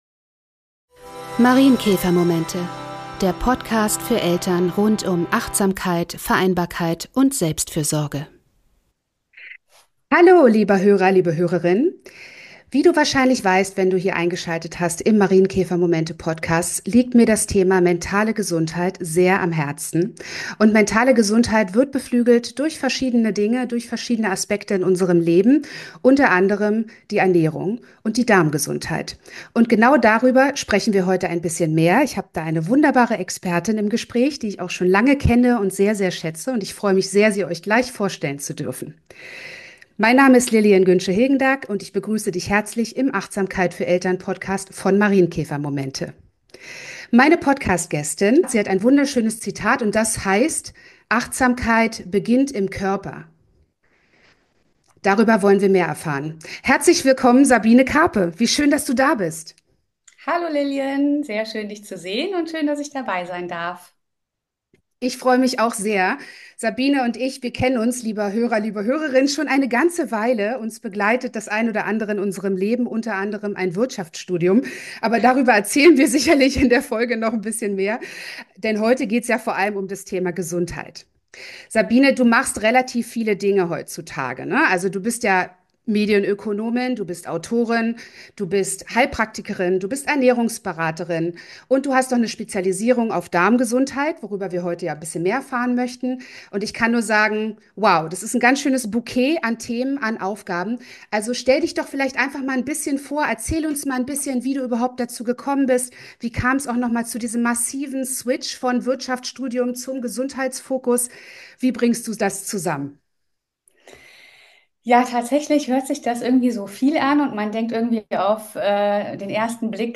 Ein sehr informatives Interviewgespräch